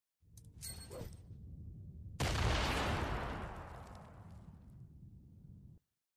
Fortnite Grenade Battle Royale